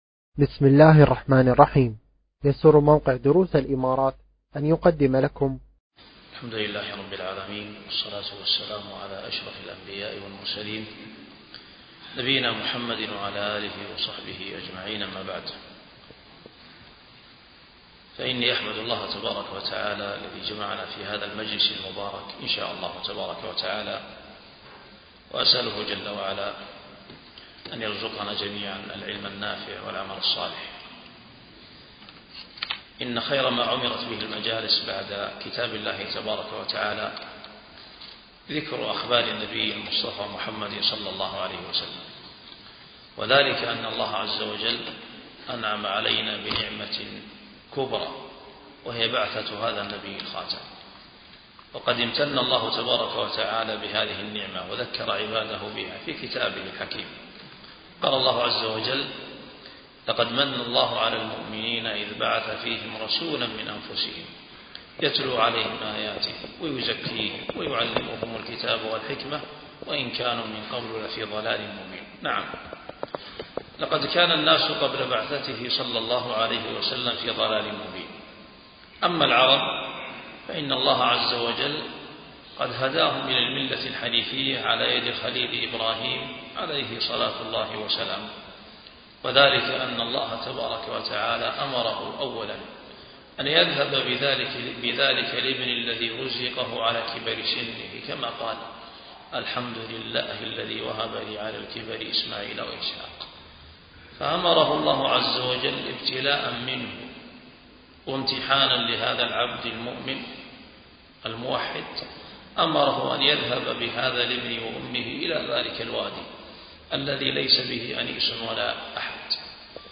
ألقيت المحاضرة في دولة الإمارات